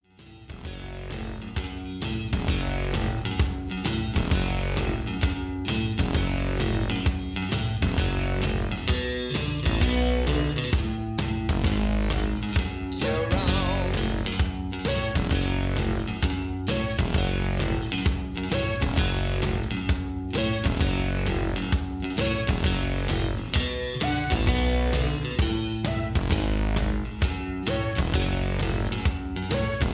Key-F#m) Karaoke MP3 Backing Tracks
Just Plain & Simply "GREAT MUSIC" (No Lyrics).